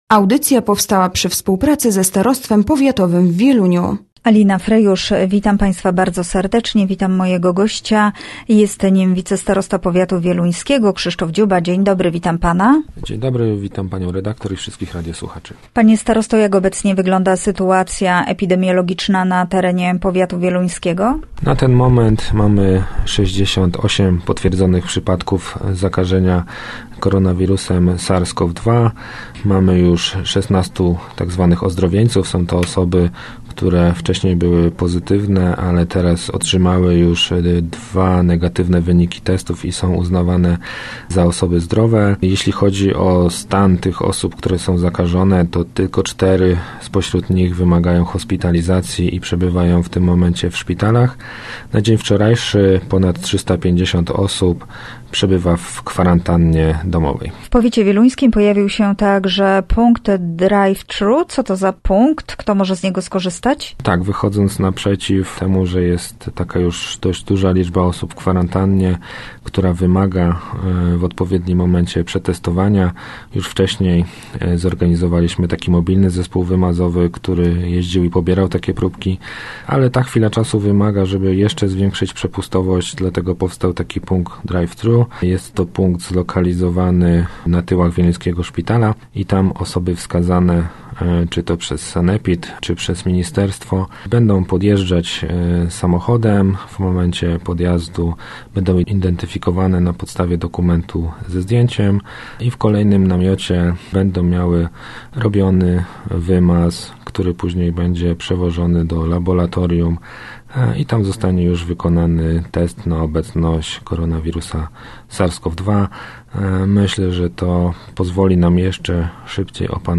Gościem Radia ZW był Krzysztof Dziuba, wicestarosta wieluński
Miedzy innymi o tym mówi w wywiadzie dla Radia ZW Krzysztof Dziuba, wicestarosta wieluński.